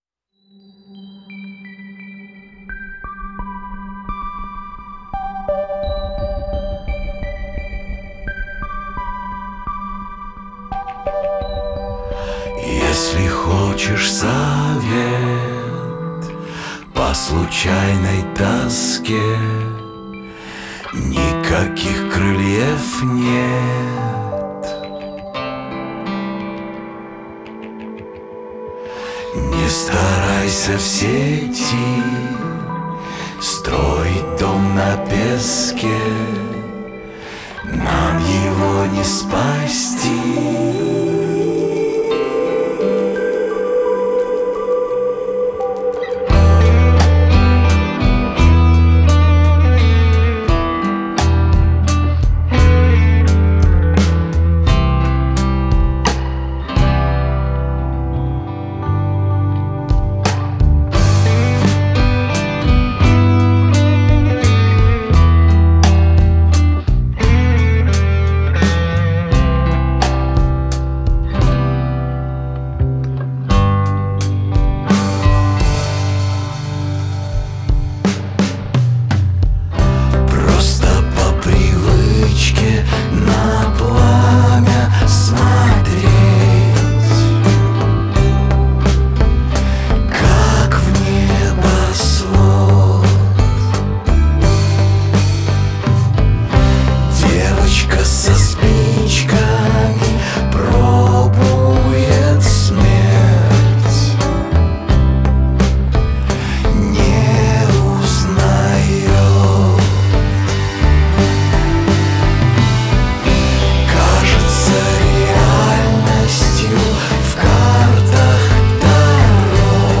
которая сочетает в себе элементы рок-музыки и поп-звучания.